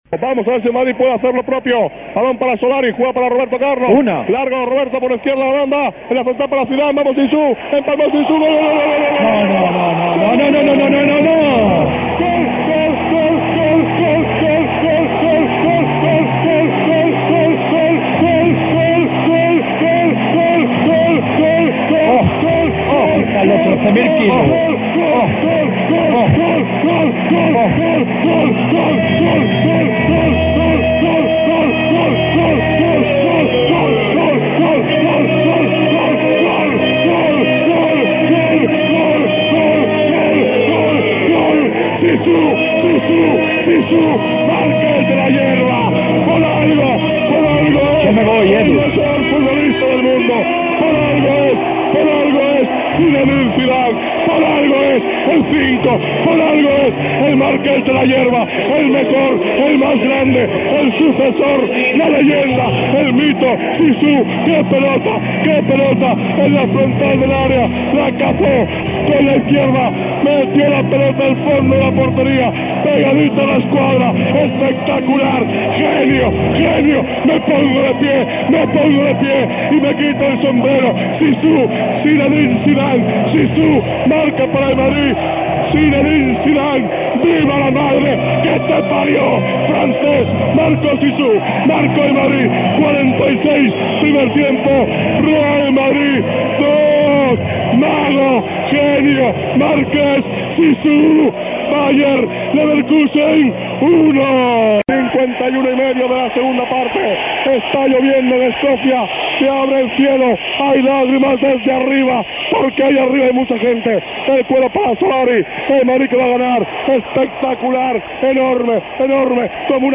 La narración es en la Cadena Cope.
Además en los siguientes audios se puede escuchar el gol de Zidane y la narración de Gaspar con emotiva dedicatoria a sus hijas y la entrega del trofeo.
gol_de_Zidane__la_9_Copa.mp3